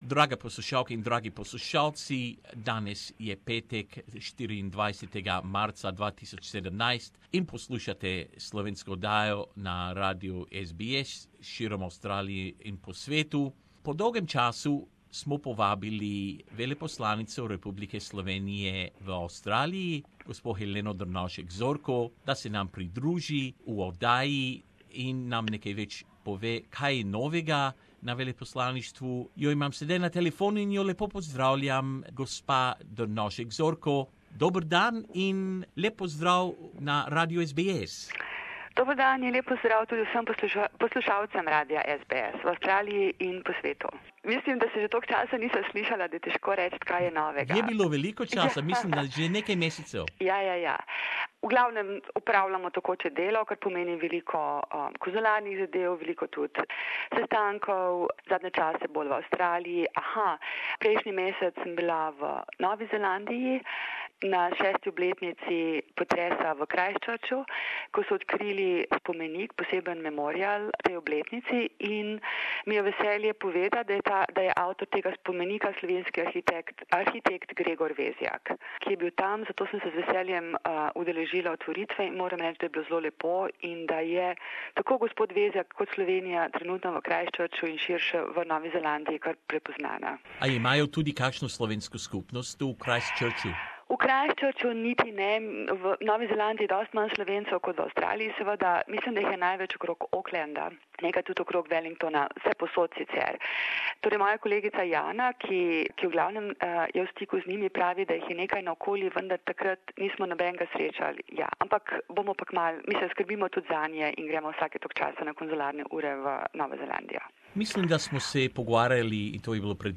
Helena Drnovšek Zorko, Ambassador of the Republic of Slovenia in Australia joined us for a chat, covering a myrid of topics. From Slovenia's stint in the UN Council for Human Rights, to members of the Australian Slovenian community participating in Sydney's Gay and Lesbian Mardi Gras for the first time this year.